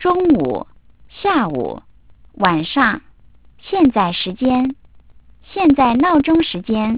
- sampling rate : 8 kHz
original speech